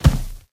sounds / material / human / step / new_wood4.ogg
new_wood4.ogg